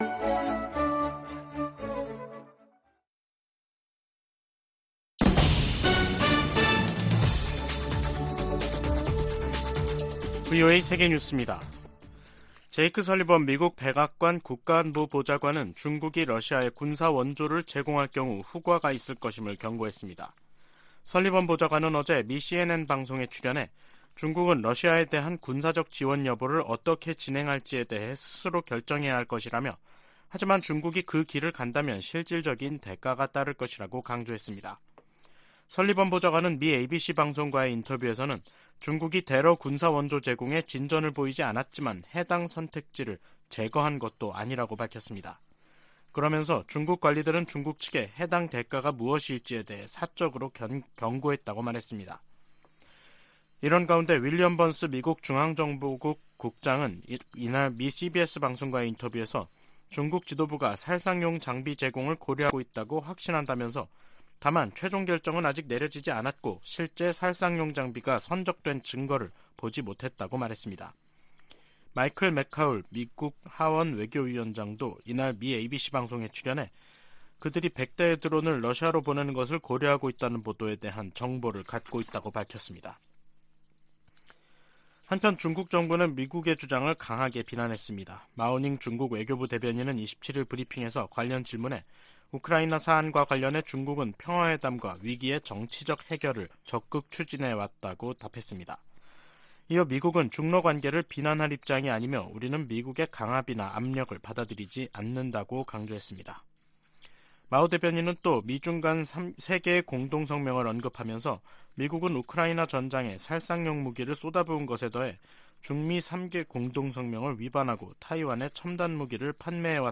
VOA 한국어 간판 뉴스 프로그램 '뉴스 투데이', 2023년 2월 27일 3부 방송입니다. 백악관은 러시아 용병조직 바그너 그룹에 북한이 무기를 지원했다고 거듭 비판했습니다. 미 국방부는 중국이 러시아 지원 카드를 완전히 내려놓지 않았다며 예의주시할 것이라고 밝혔습니다. 남-북한은 유엔총회에서 바그너 그룹에 대한 북한의 무기거래 문제로 설전을 벌였습니다.